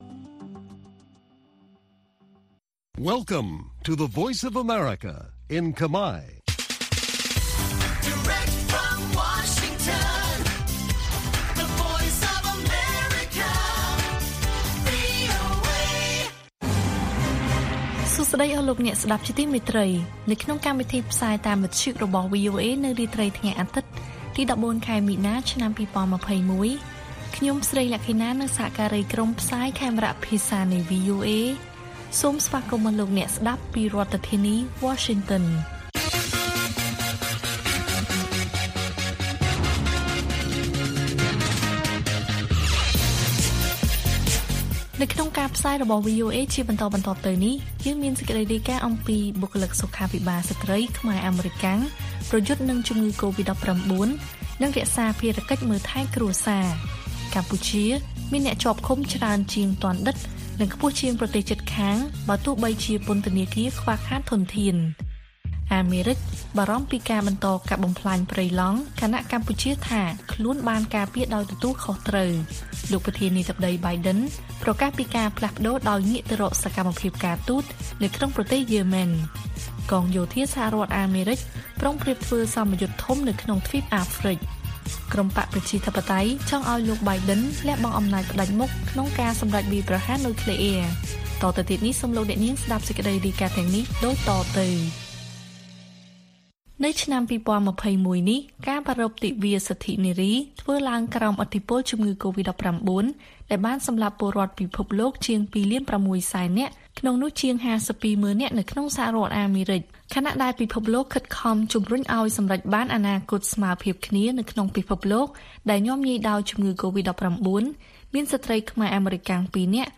ព័ត៌មានពេលរាត្រី៖ ១៤ មីនា ២០២១